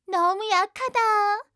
1 channel
ui_yell_0_15.wav